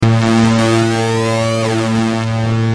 Free MP3 vintage Sequential circuits Pro-5 loops & sound effects 2